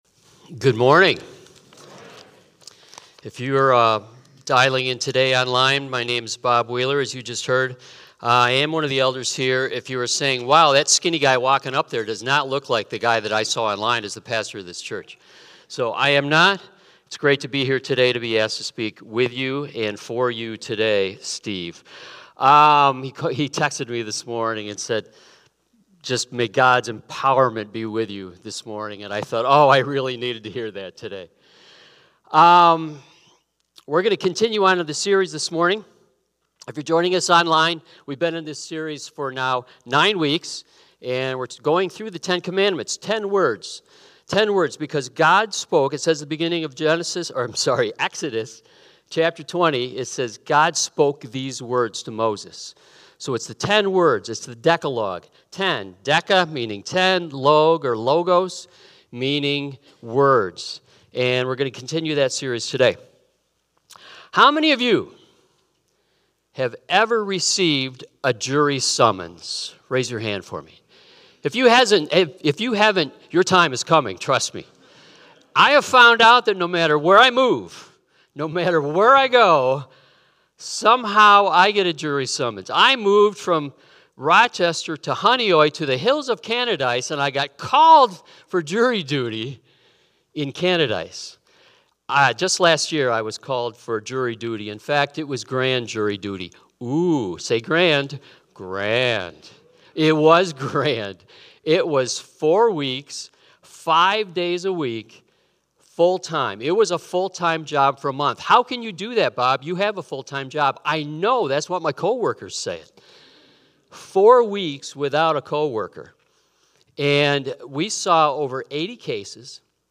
Victor Community Church Sunday Messages / 10 Words: Speak Honestly (September 7th, 2025)